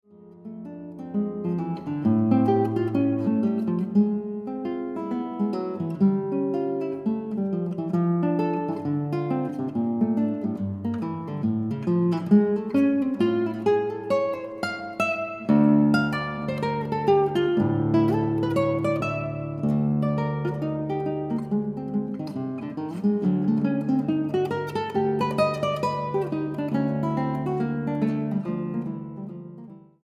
guitarra.
Vivo e marcato